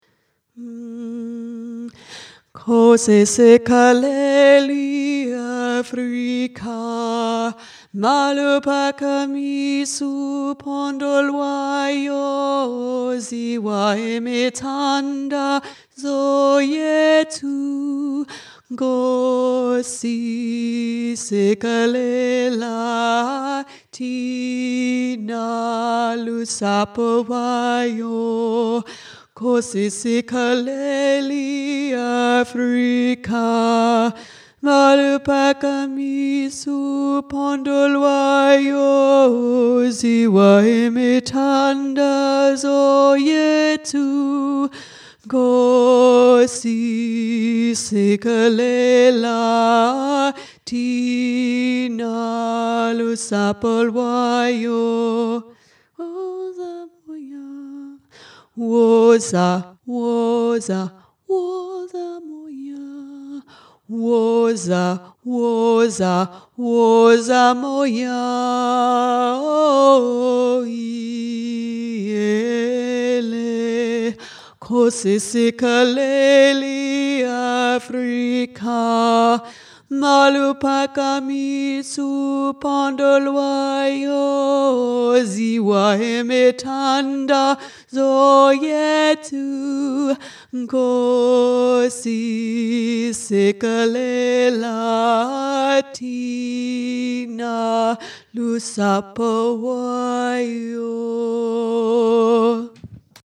nkosi-tenor
nkosi-tenor.mp3